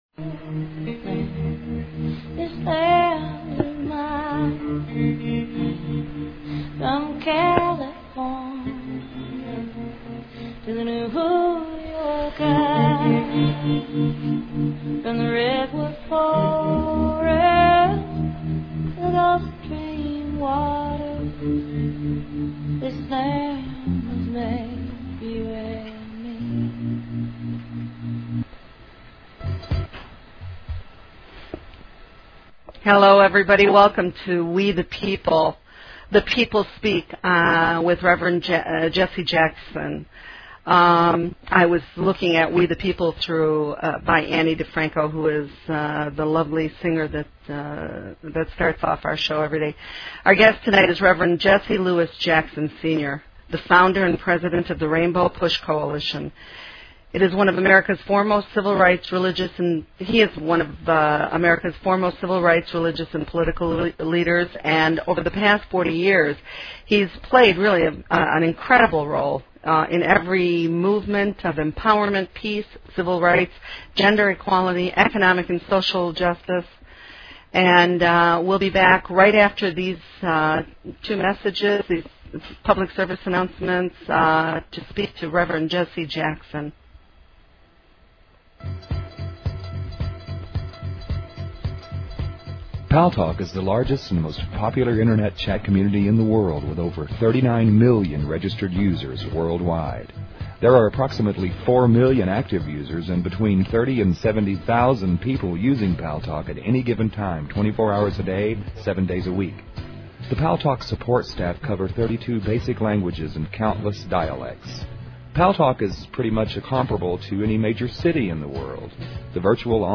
Guest, Jesse Jackson
The People Speak with guest Jesse Jackson